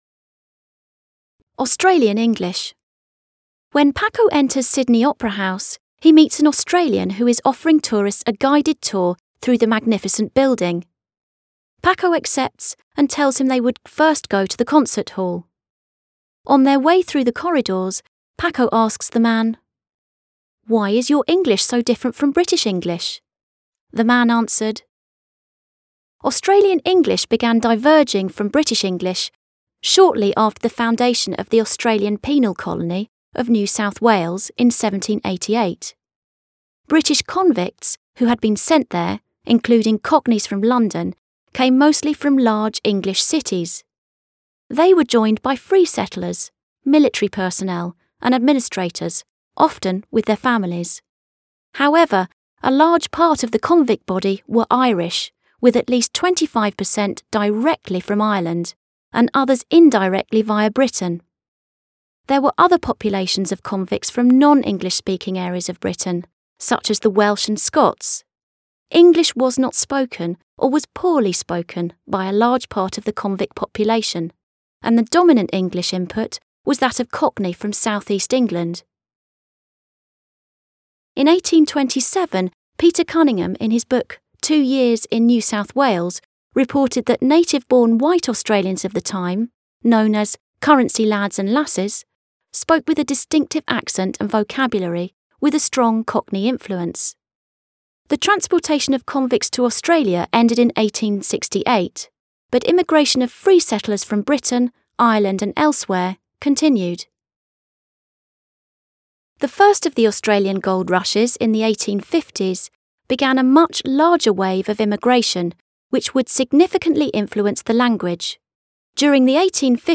Audio Australian English When Paco enters Sydney Opera House, he meets an Australian who is offering tourists a guided tour through the magnificent building.